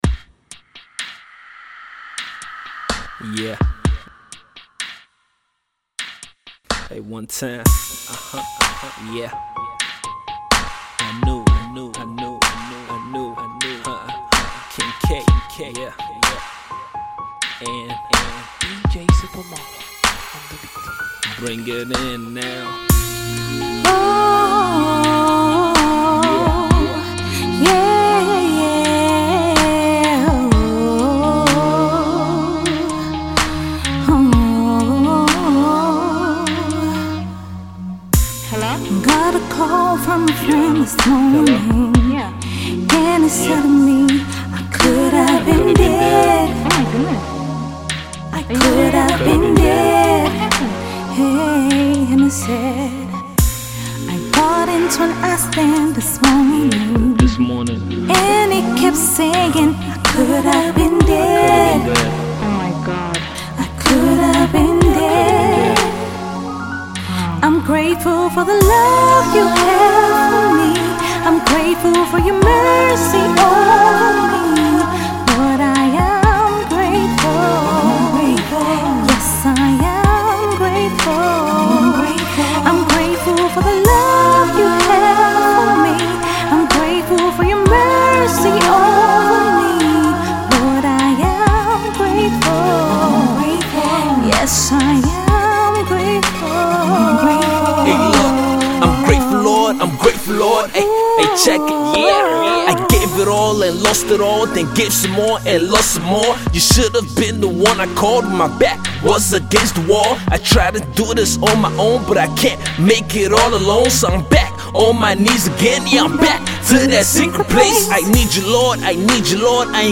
Nigerian Gospel music